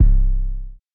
REDD 808 (16).wav